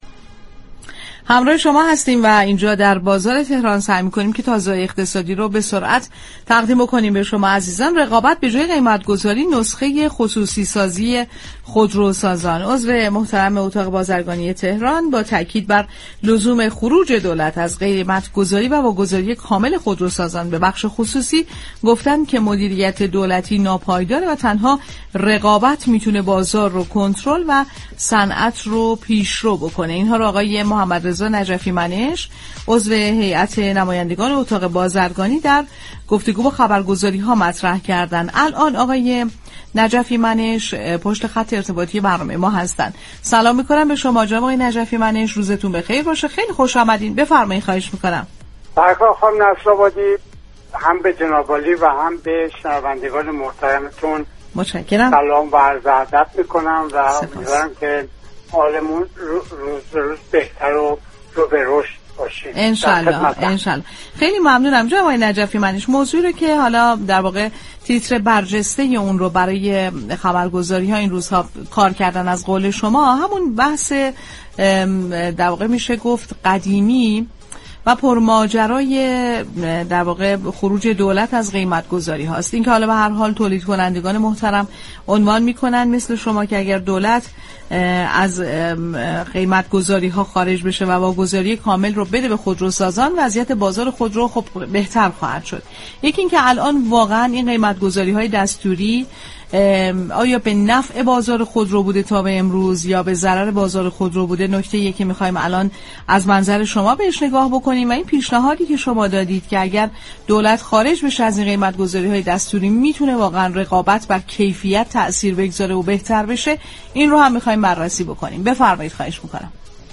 در گفت و گو